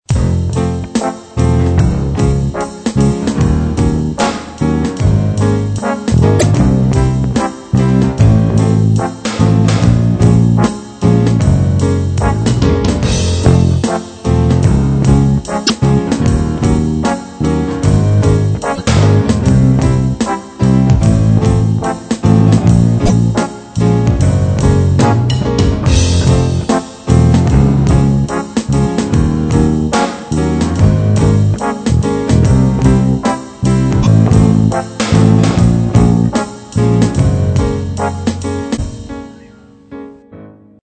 Pas de paroles
• Synthé: KORG T3ex